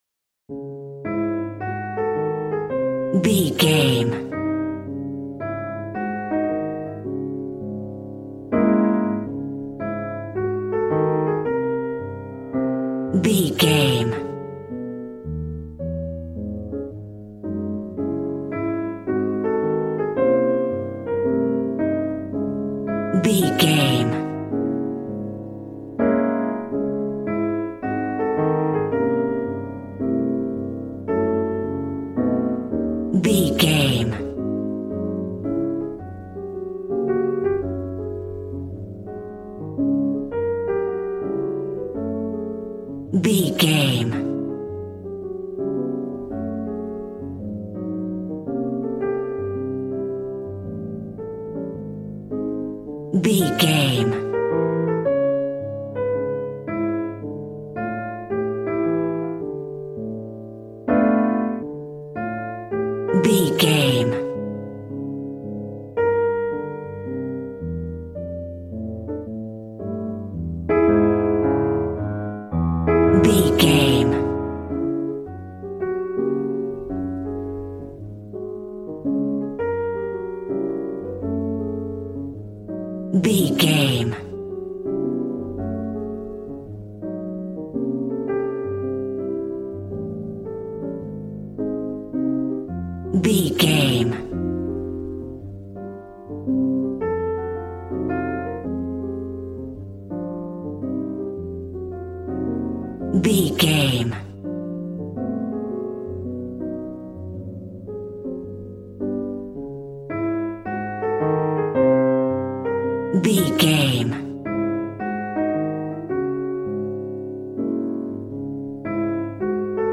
Ionian/Major
smooth
piano
drums